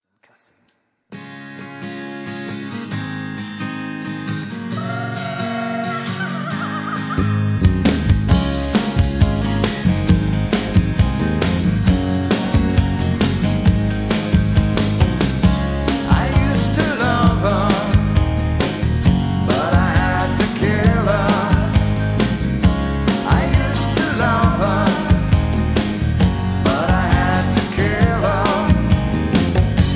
backing tracks
rock